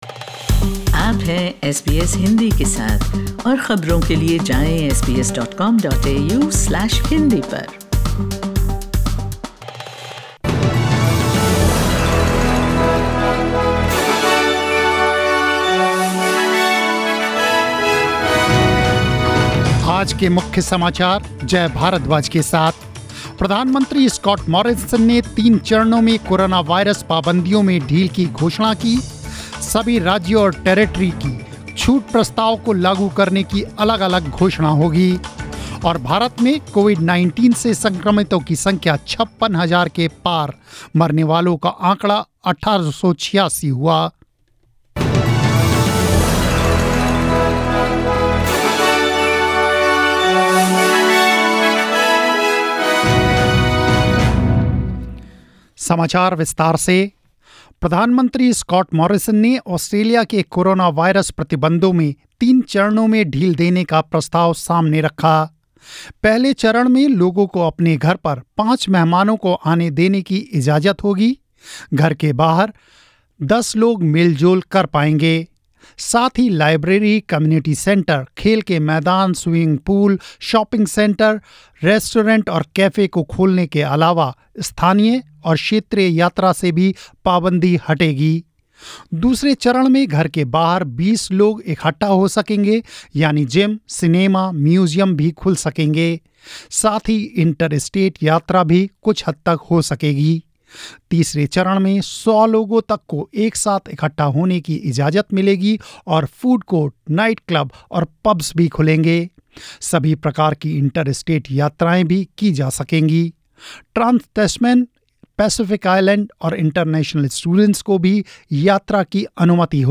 News in Hindi 08 May 2020